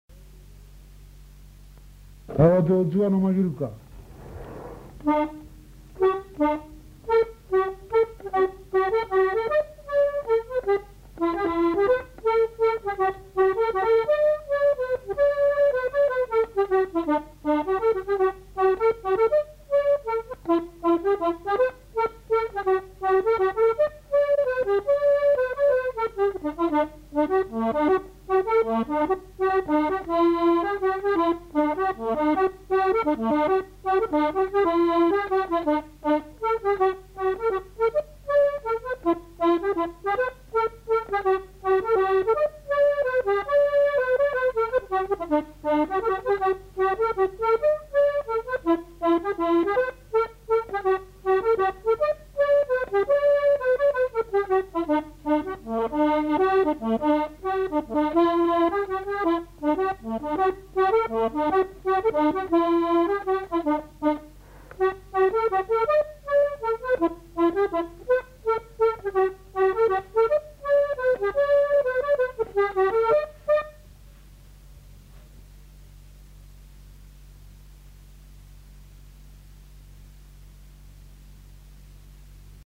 Mazurka
Aire culturelle : Haut-Agenais
Genre : morceau instrumental
Instrument de musique : accordéon chromatique
Danse : mazurka